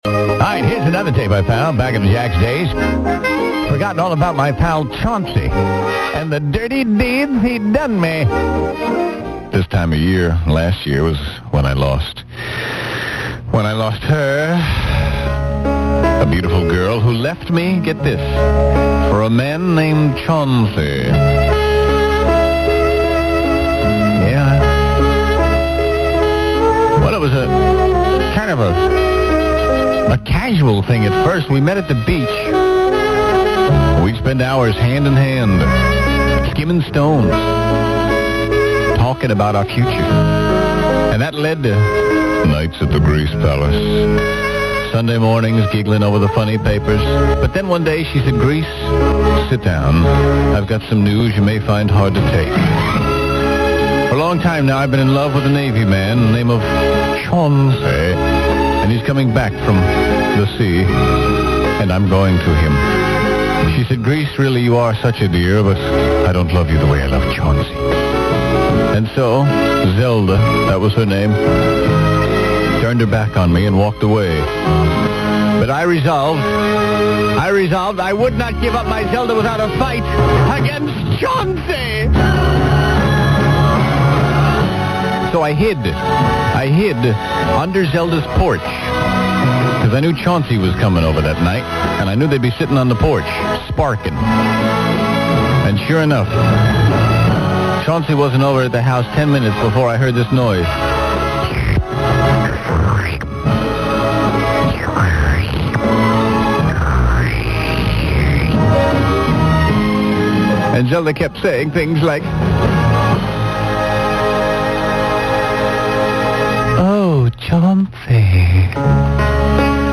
On January 30th, 1999 (the day before the Super Bowl, get it?), Classic Rock 94.7 played a collection of classic bits, including some from Grease's early days on Florida radio in the 70's for the first time in 20 years!